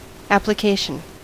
Ääntäminen
IPA : [ˌæ.plə.ˈkeɪ.ʃən]